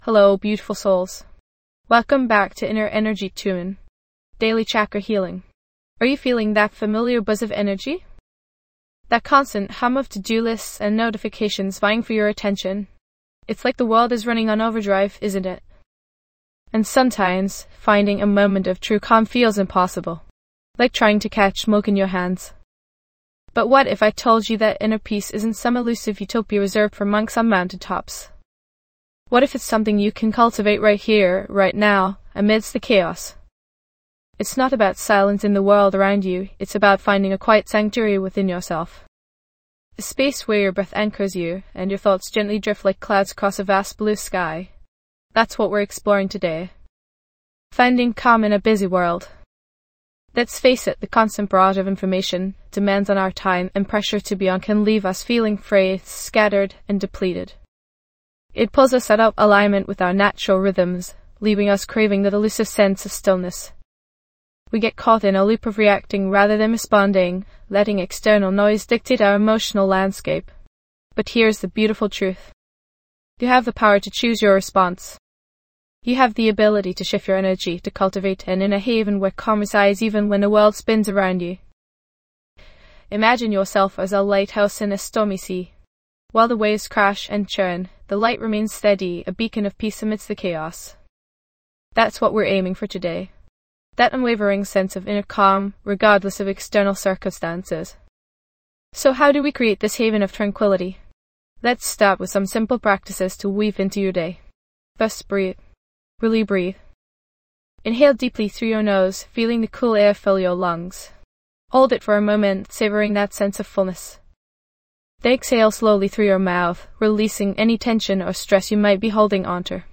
Each day, we delve into one specific chakra, exploring its unique energy center, its associated emotions, and practical techniques to balance and harmonize it. Through guided meditations, soothing soundscapes, and insightful teachings, this podcast empowers you to release blockages, cultivate emotional stability, and tap into your full potential.